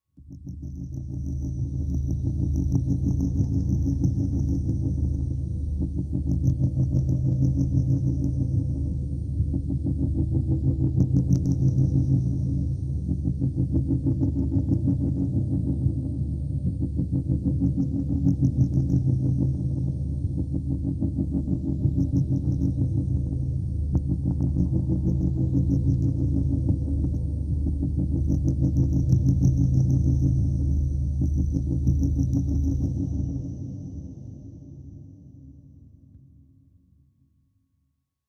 Nuclear Insects-Pulsing tone with hi pitched chirping noises looped